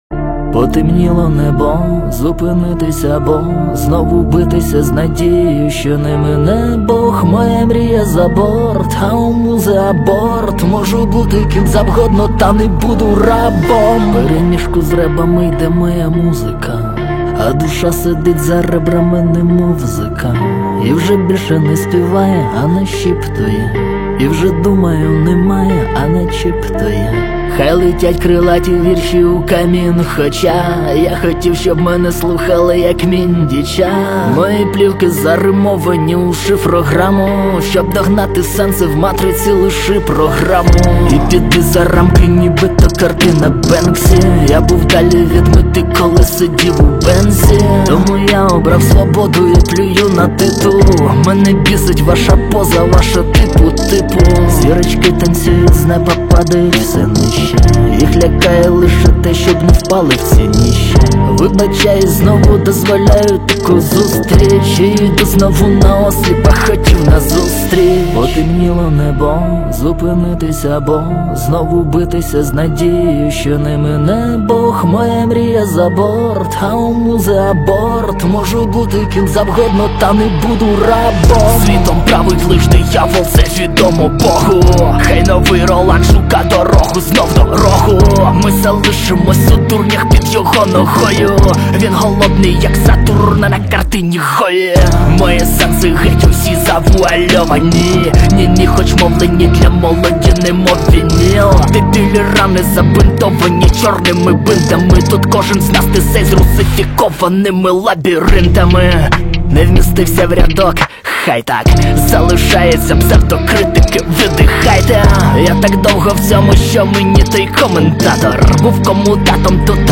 Жанр: Реп / хіп-хоп